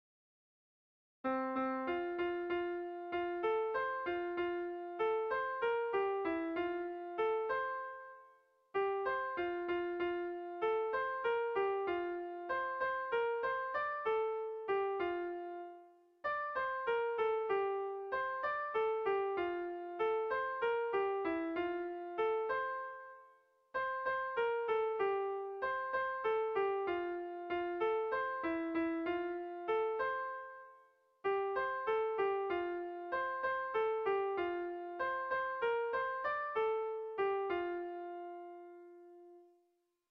Erlijiozkoa
A1A2A3A3A2